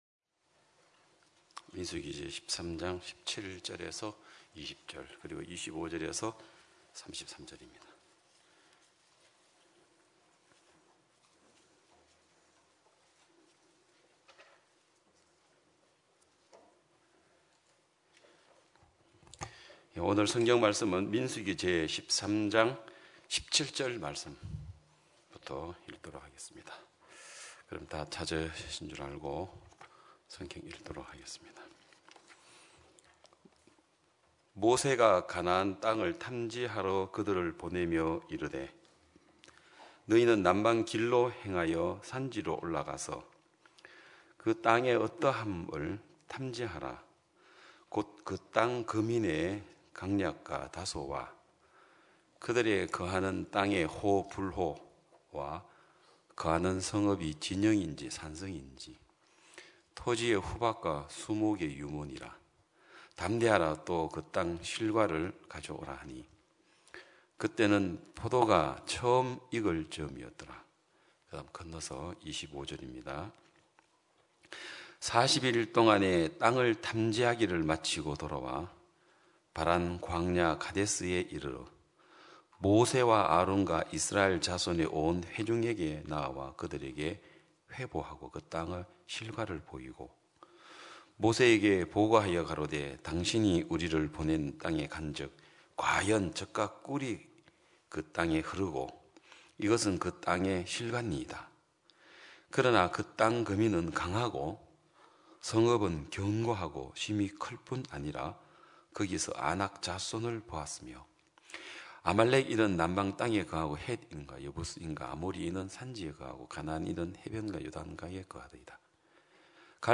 2022년 5월15일 기쁜소식양천교회 주일오전예배
성도들이 모두 교회에 모여 말씀을 듣는 주일 예배의 설교는, 한 주간 우리 마음을 채웠던 생각을 내려두고 하나님의 말씀으로 가득 채우는 시간입니다.